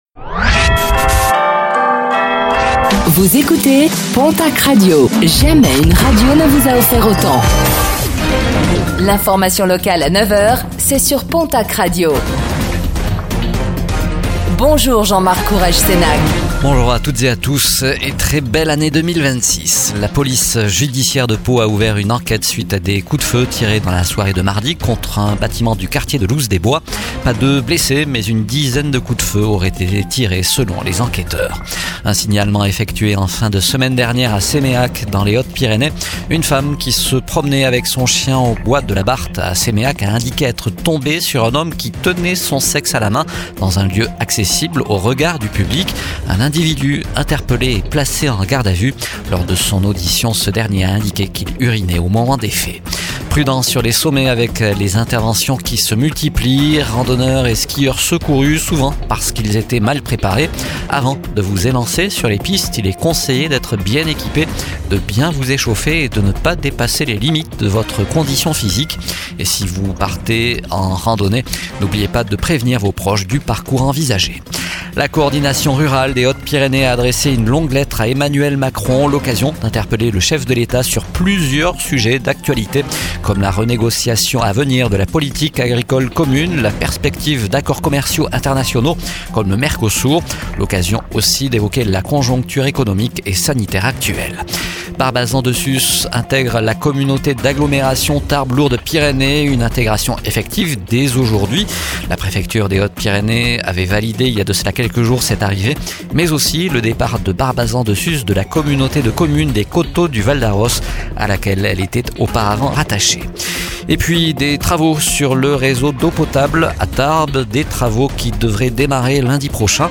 Réécoutez le flash d'information locale de ce jeudi 1er janvier 2026